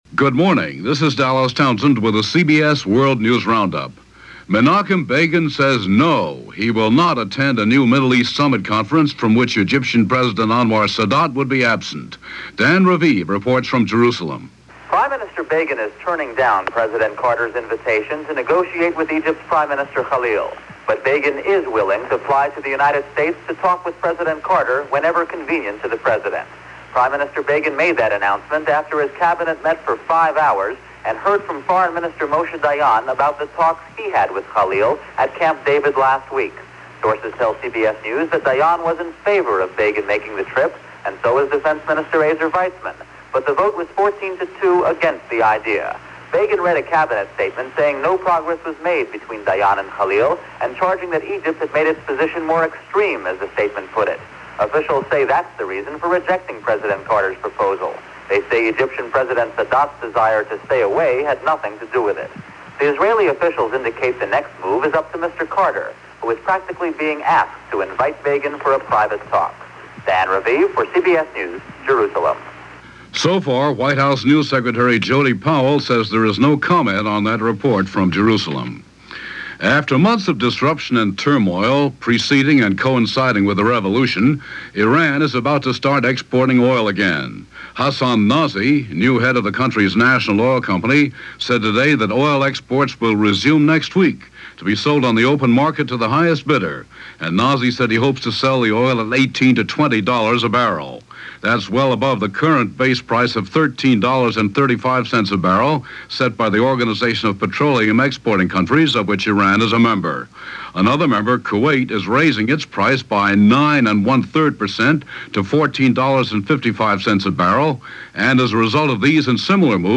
CBS World News Roundup